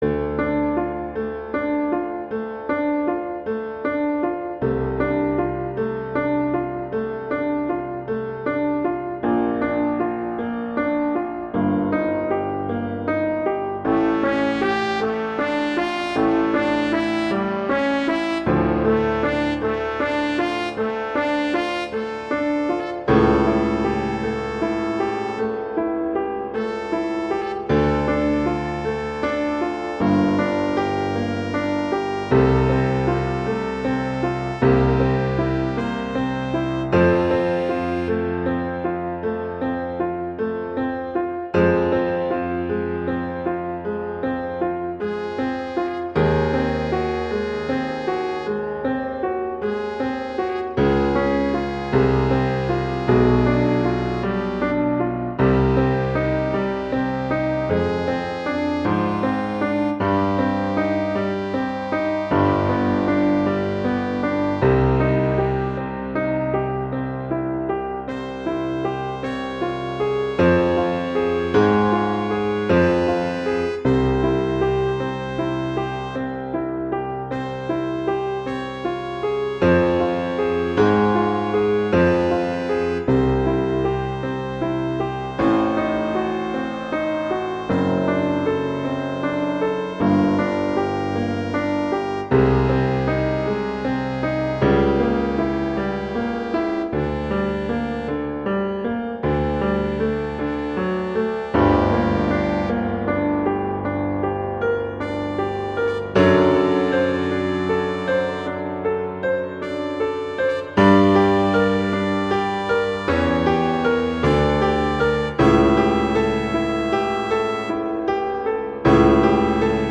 arrangements for trumpet and piano
classical, french